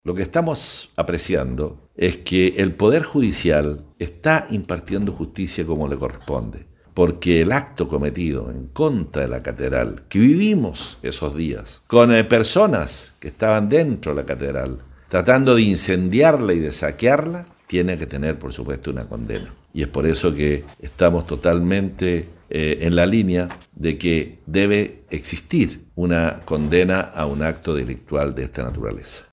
Esta primera resolución de la Corte Suprema fue valorada por la intendencia regional de Los Lagos, quien es parte querellante en dicha causa, como lo detalla el intendente Harry Jürgensen. La autoridad política de Gobierno en Los Lagos, dijo que acá el poder judicial está aplicando justicia respecto de lo grave que fue el ataque a la catedral que incluso se encontraban personas en el interior al momento del hecho.